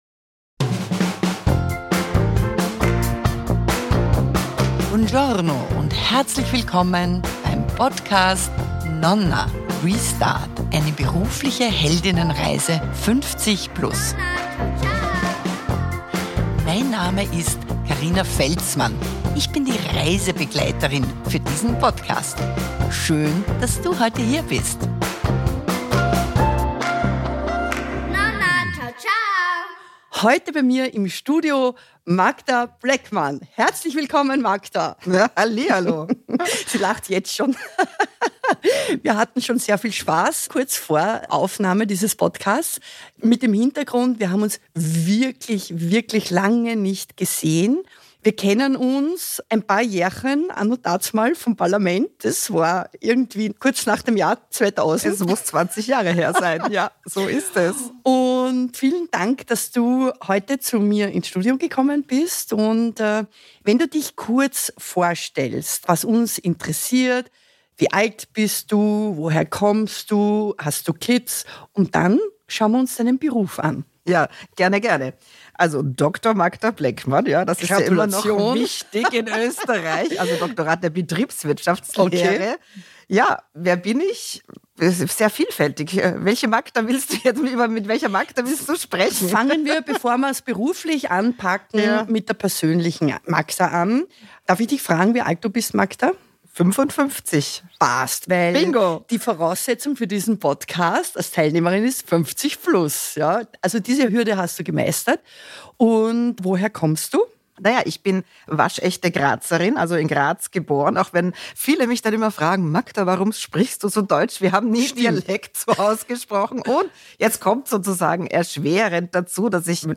interviewt zur beruflichen Held*innenreise